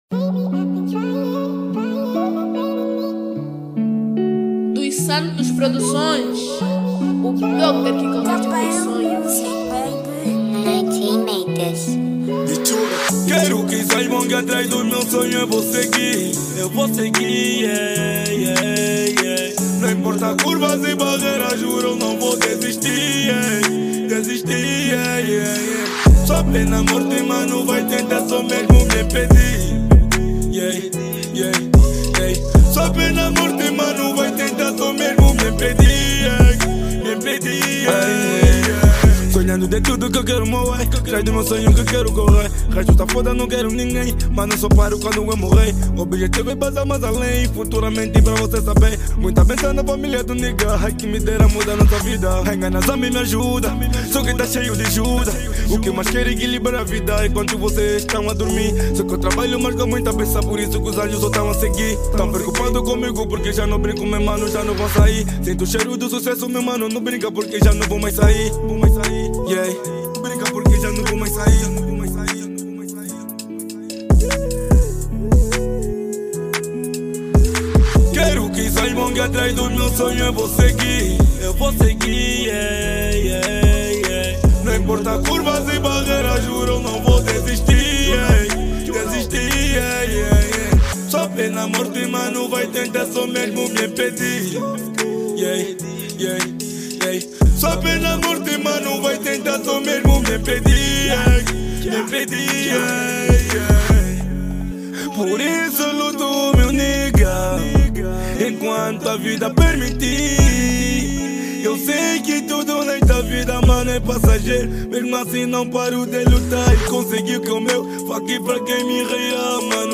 Categoria Rap/Trap